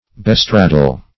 Bestraddle \Be*strad"dle\, v. t.